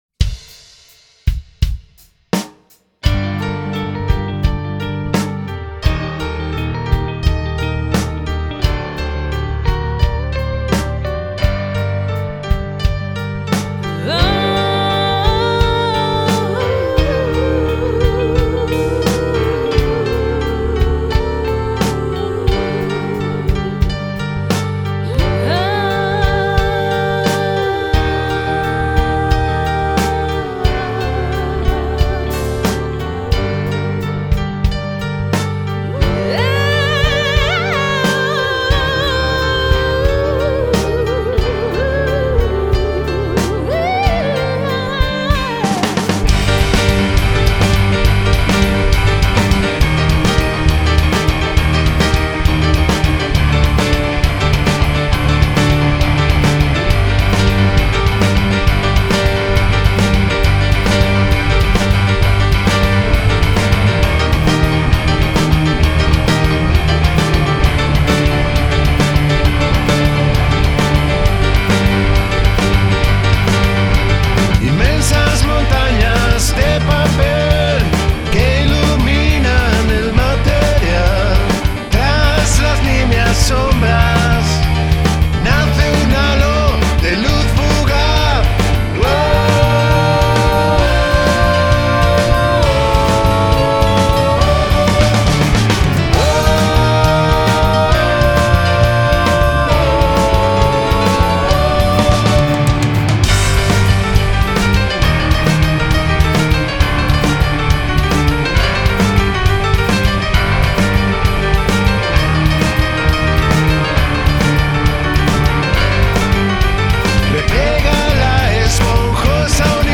Genero: pop-rock experimental